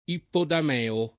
[ ippodam $ o ]